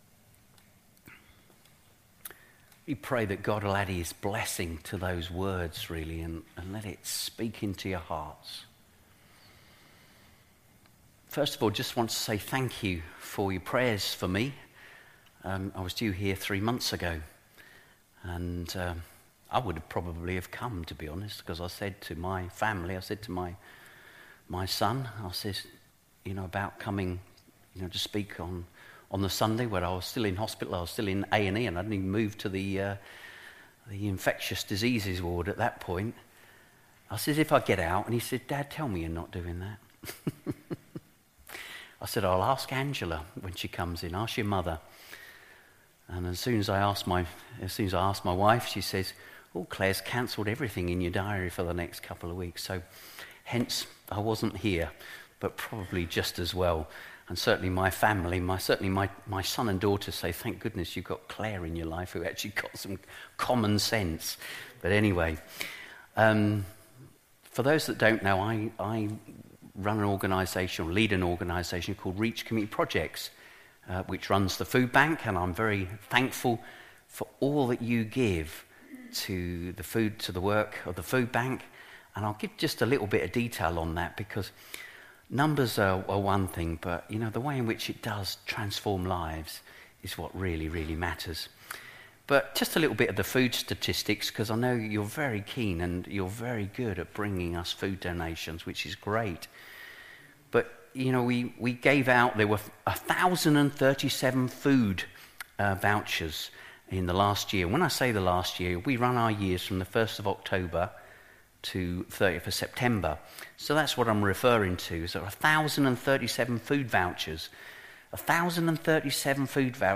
The sermon is also available as an audio download.
11-24-sermon.mp3